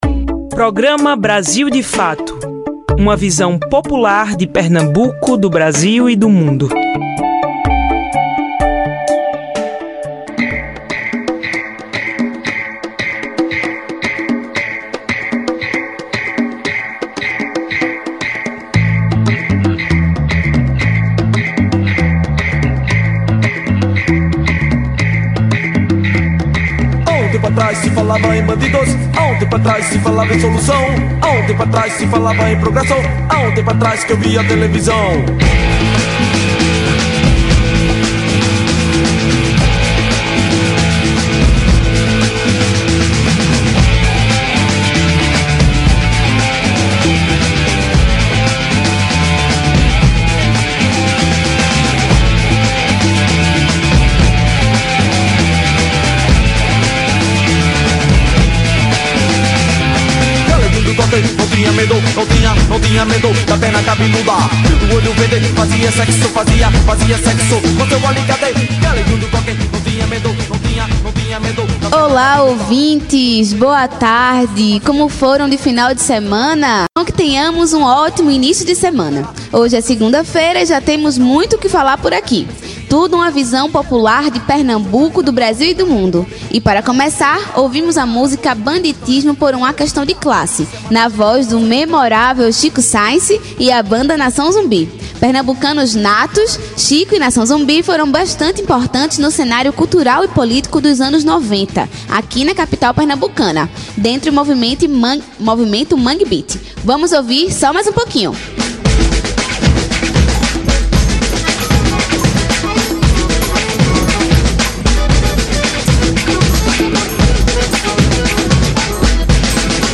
Ouça a entrevista com Sérgio Mamberti no Programa Brasil de Fato Pernambuco